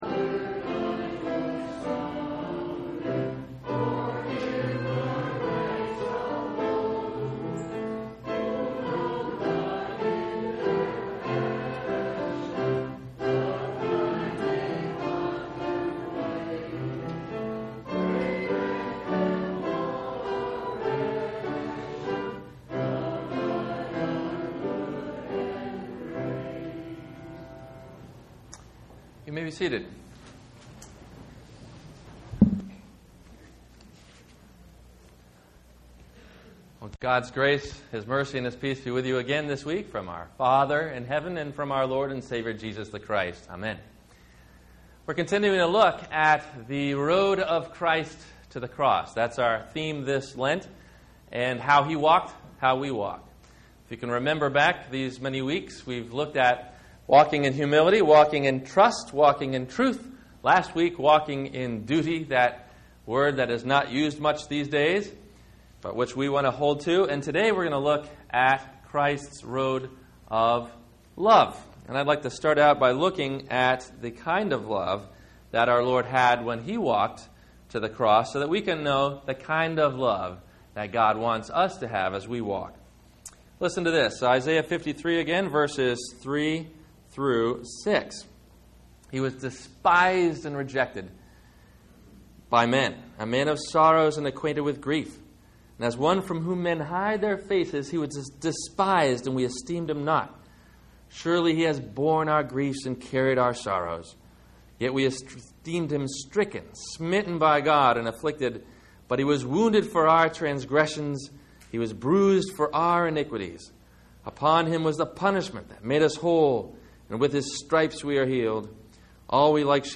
Lent week 4 - Sermon - March 25 2009 - Christ Lutheran Cape Canaveral